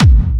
VEC3 Clubby Kicks
VEC3 Bassdrums Clubby 029.wav